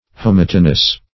Search Result for " homotonous" : The Collaborative International Dictionary of English v.0.48: Homotonous \Ho*mot"o*nous\, a. [L. homotonus, Gr.
homotonous.mp3